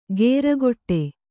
♪ gēragoṭṭe